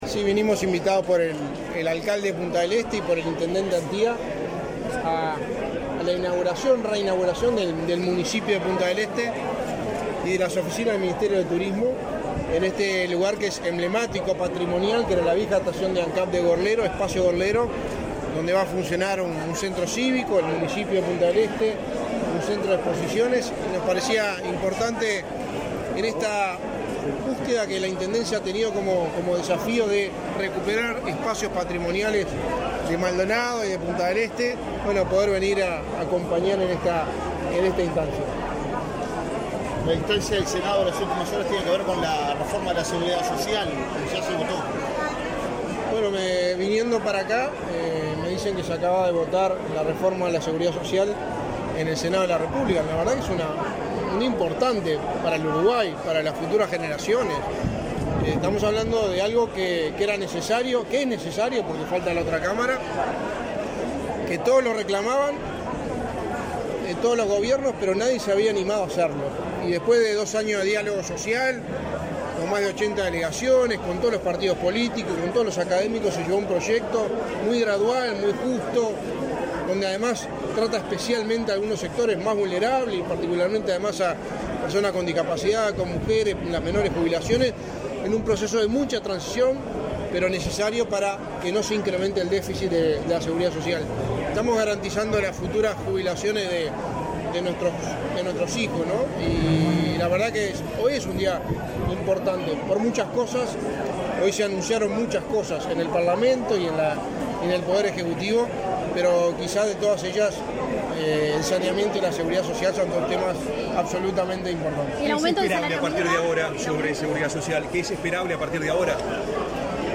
Declaraciones a la prensa del secretario de la Presidencia, Álvaro Delgado
Declaraciones a la prensa del secretario de la Presidencia, Álvaro Delgado 29/12/2022 Compartir Facebook X Copiar enlace WhatsApp LinkedIn Este 28 de diciembre fue inaugurado el Espacio Gorlero en vieja estación Ancap de Gorlero, en Punta del Este. En el evento participó el secretario de la Presidencia, Álvaro Delgado.